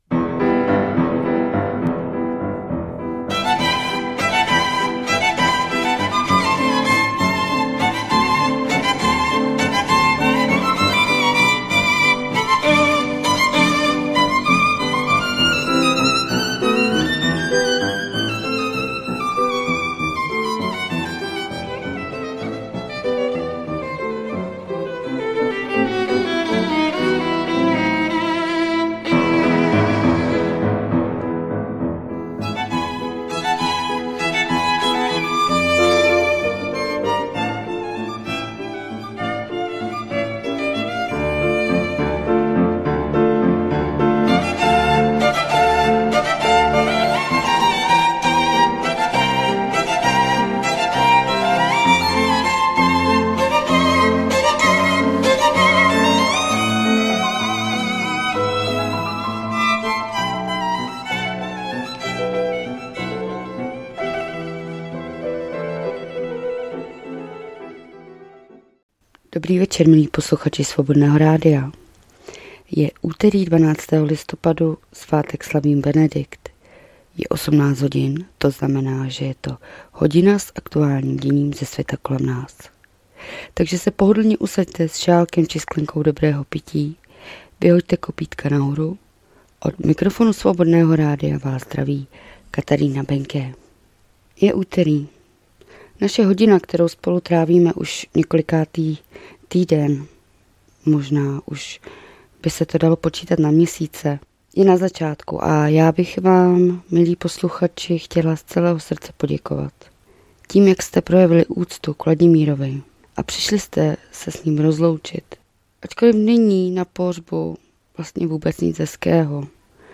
2024-11-12 - Zpravodajský přehled.